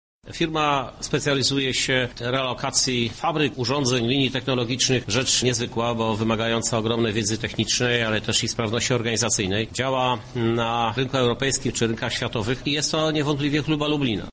Nie jestem zaskoczony wyborem to tego projektu właśnie lubelskiego przedsiębiorstwa – mówi Prezydent Lublina Krzysztof Żuk: